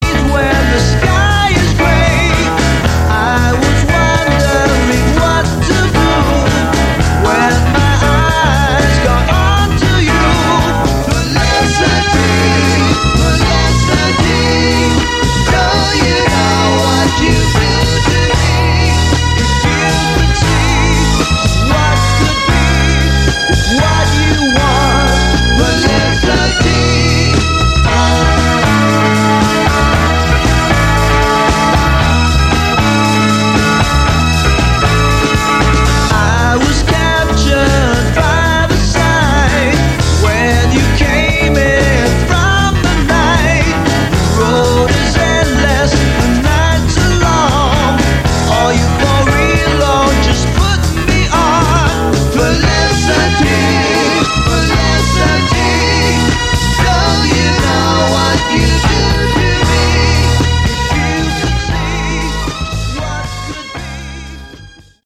Category: Melodic Rock
lead vocals, backing vocals
guitar
keyboards
bass
drums